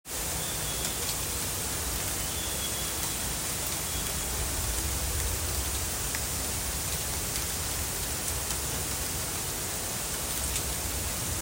It’s a rainy morning, and I’ve bottled up a little of its sound to share with you, anons.